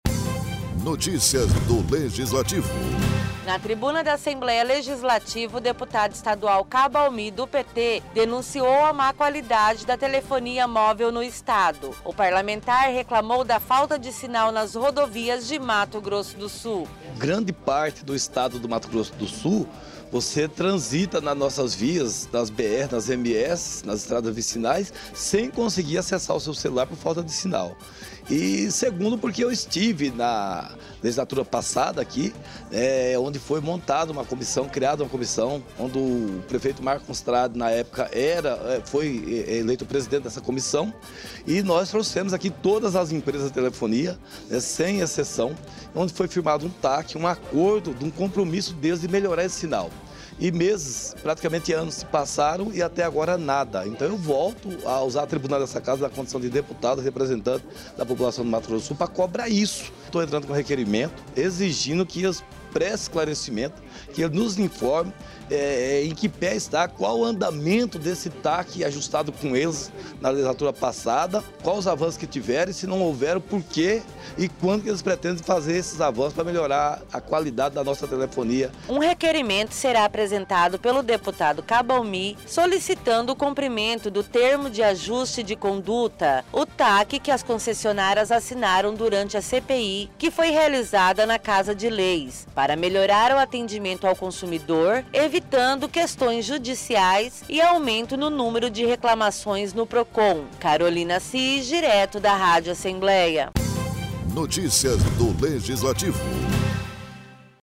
O deputado estadual Cabo Almi, do PT usou a tribuna da Assembleia Legislativa nesta quarta-feira para denunciar a má qualidade da telefonia móvel em Mato Grosso do Sul.